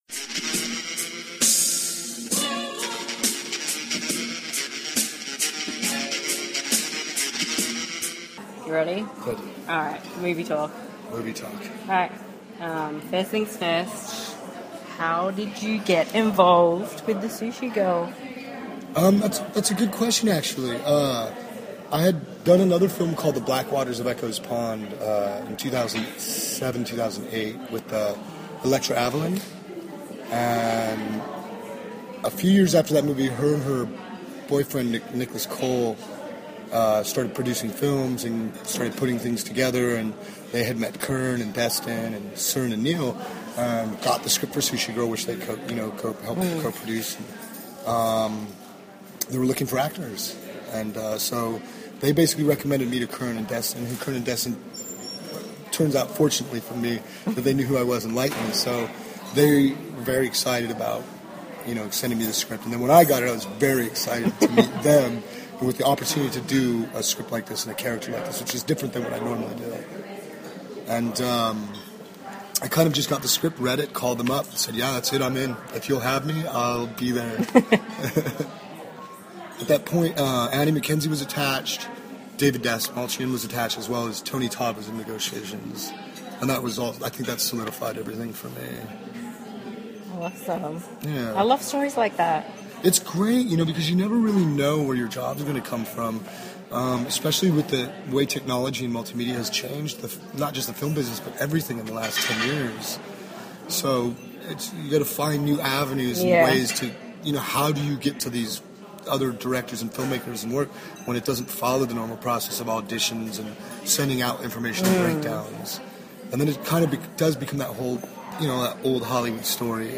Interview: James Duval (Sushi Girl)